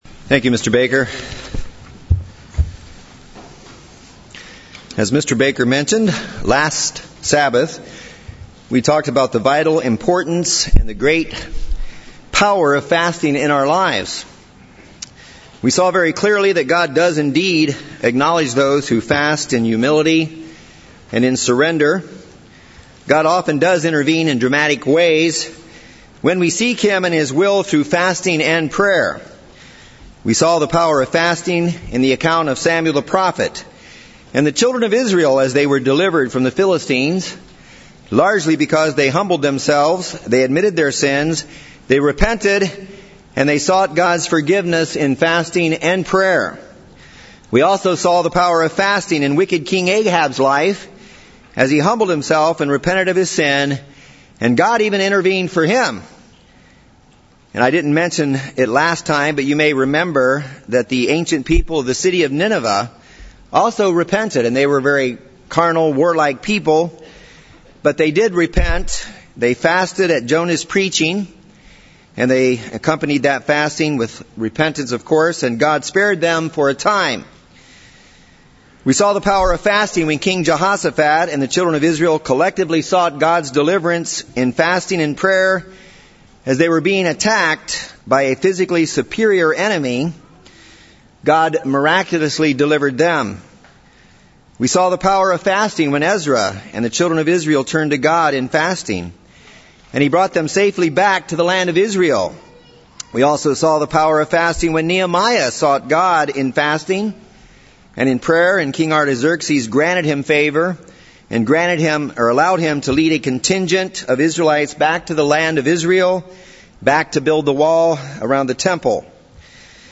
In this sermon I would like to show you the importance and power of fasting as revealed in the Bible.